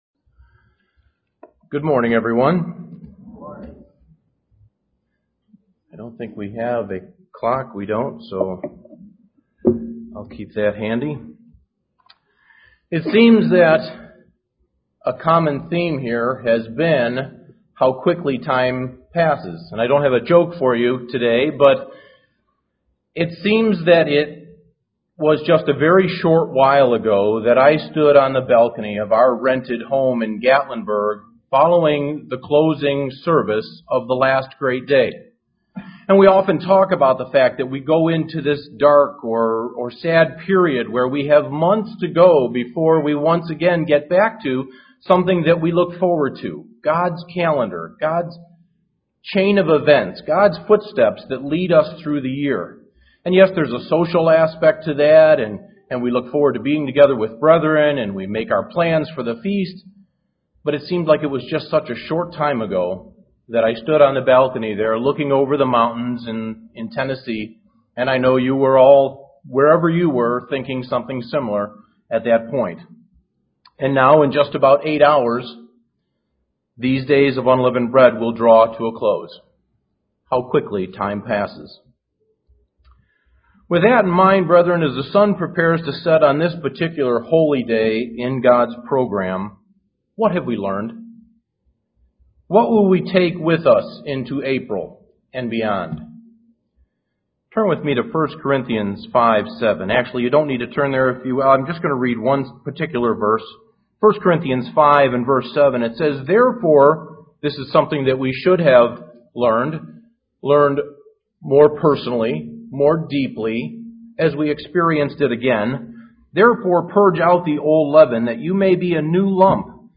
Given in Elmira, NY
UCG Sermon Studying the bible?